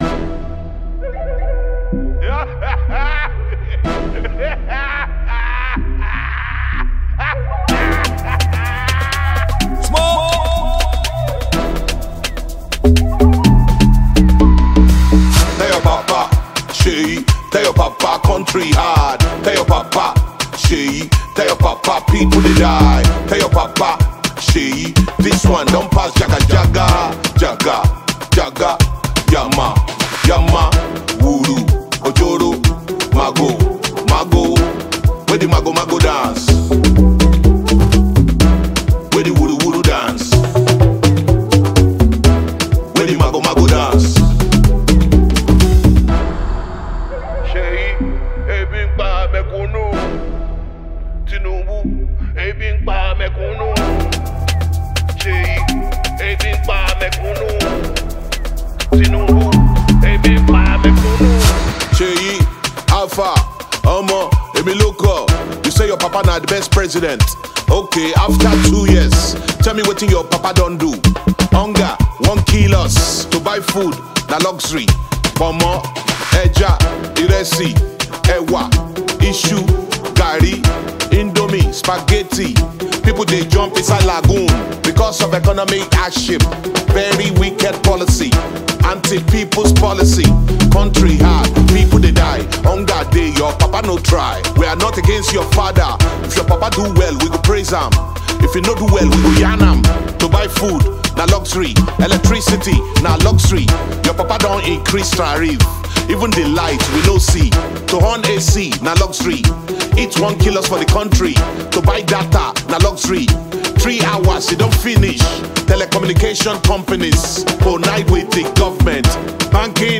Nigerian hip hop singer